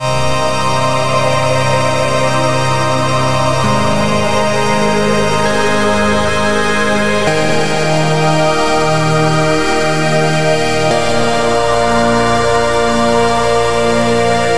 鼓环打击乐器节拍
描述：鼓环打击乐器节拍.Breakbeat Drum Loop，打击乐器140 bpm。 迪斯科质朴的节拍。 跳动样本。
Tag: 节奏循环 打击乐器 绝妙 140BPM 样本 循环 背景声 鼓循环 节拍 碎拍 堂鼓